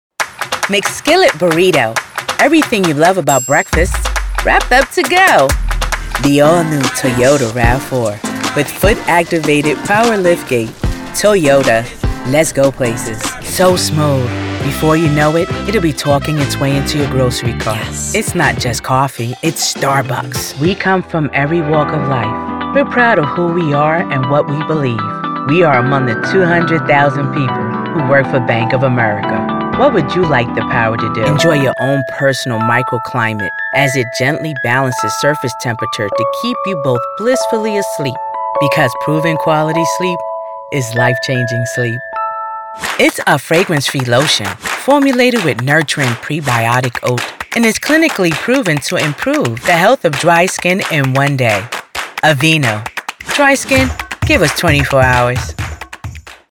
Voiceover artist COMMERCIAL DEMO
Voiceover artist